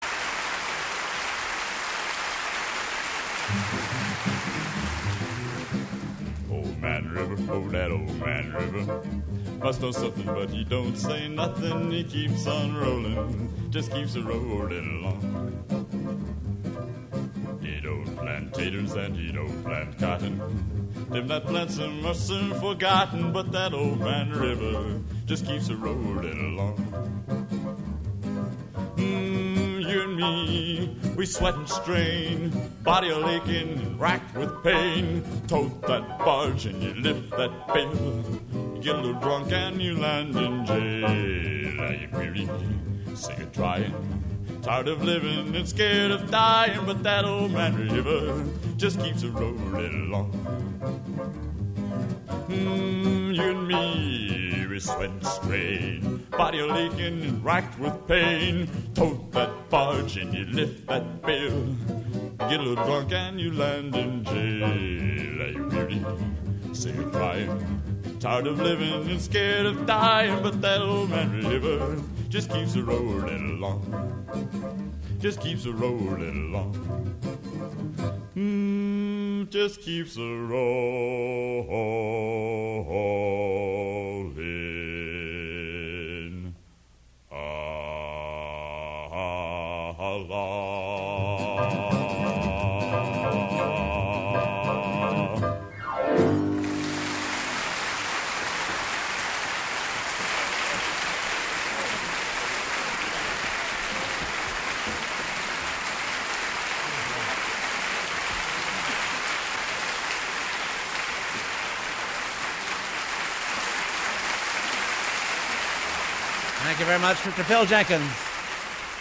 Senior Cane Ceremony
Genre: | Type: End of Season
Guitar
Bass
Piano